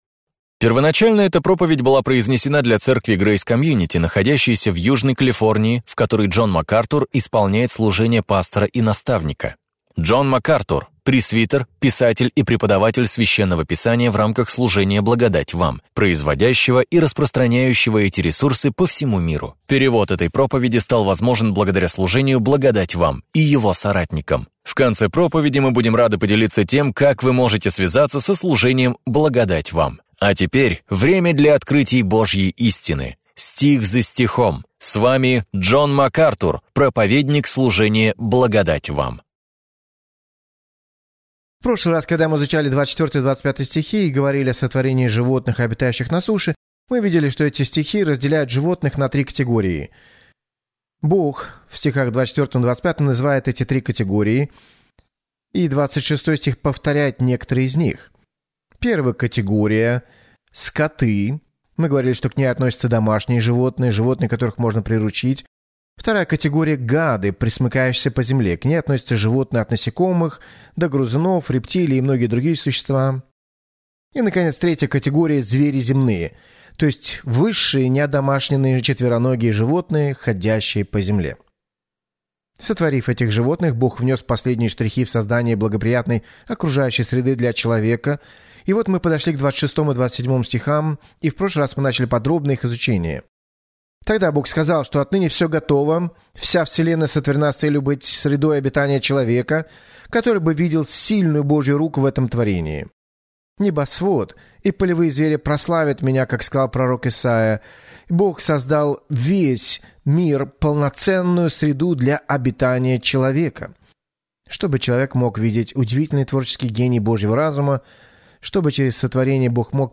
Проповеди МакАртура